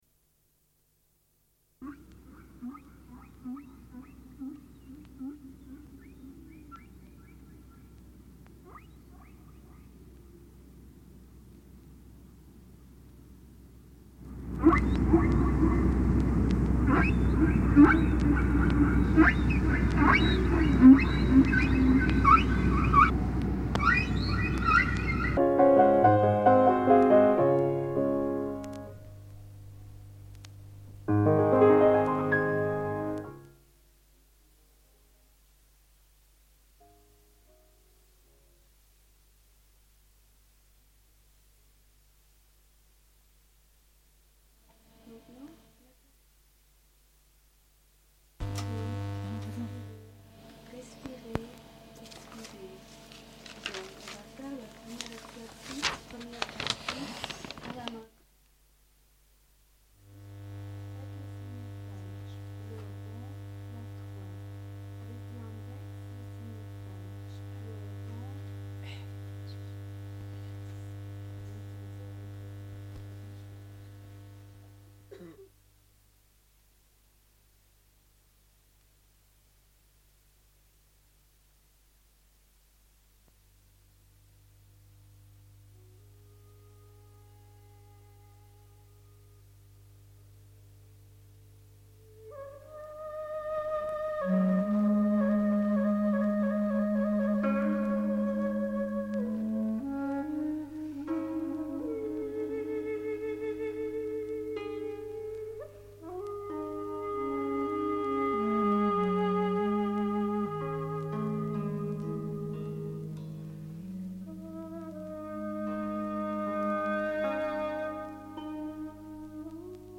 Pour commencer l'année 1983, RPL organise un « marathon » de 24h de radio.
Une cassette audio Face A
// 00:12:16 Musique 00:16:42 // Informations entrecoupées de musique. // 00:27:15 00:29:48 // Présentation du programme de la journée.